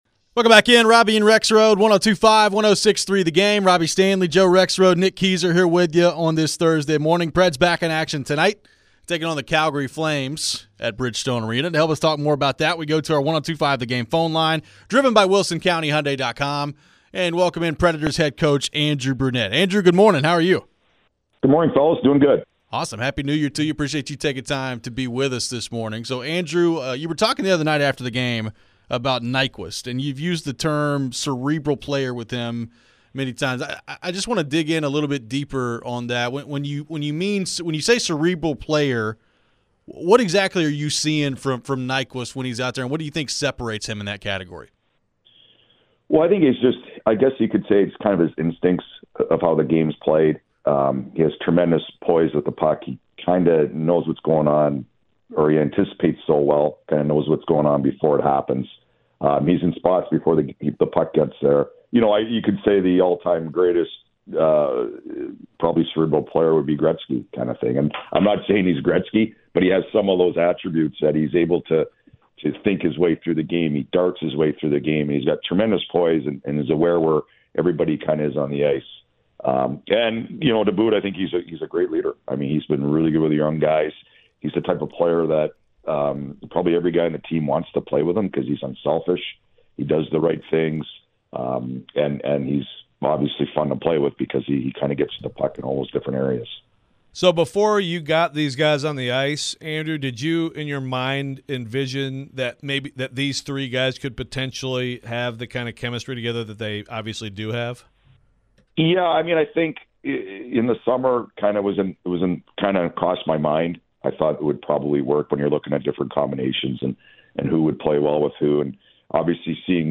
Andrew Brunette Interview (1-4-24)
Nashville Predators head coach Andrew Brunette joined the show to discuss what he's seen from his team lately. What has he made of Gustav Nyquist's play the last little bit? Are the younger players on pace for where he'd like them to be right now?